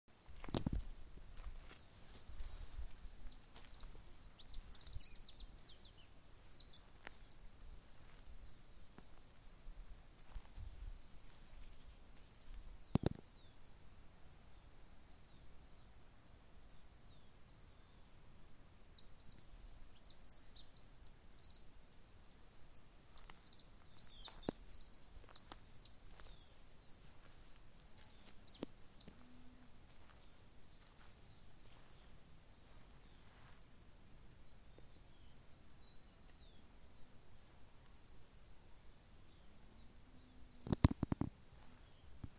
Canário-da-terra-verdadeiro (Sicalis flaveola)
Nome em Inglês: Saffron Finch
Localidade ou área protegida: Ruta 40 tolombon
Condição: Selvagem
Certeza: Gravado Vocal
Jilguerodorado.mp3